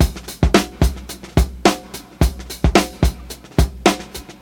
109 Bpm 00's Drum Loop C Key.wav
Free drum loop - kick tuned to the C note. Loudest frequency: 1260Hz
109-bpm-00s-drum-loop-c-key-MMD.ogg